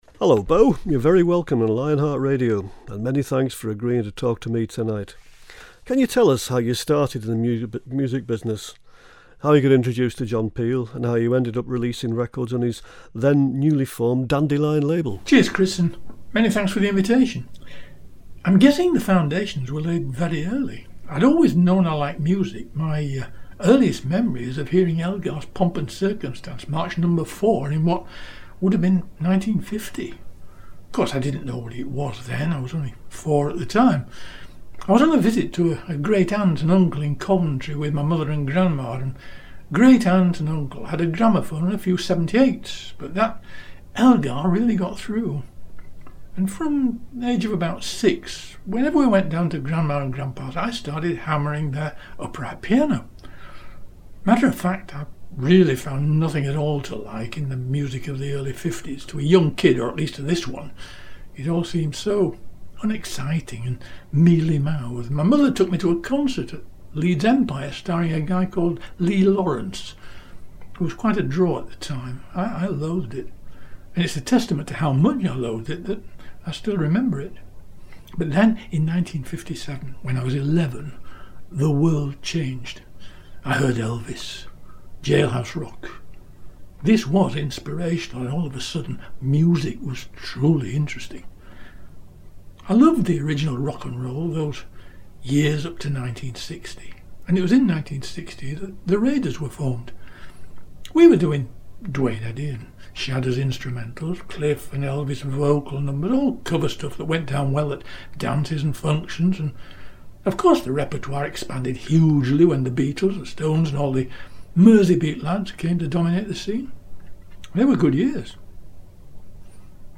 they’ve just posted the “chat audio” from the show This is just an edit of the audio – no music – so there are a couple of linkage abnormalities.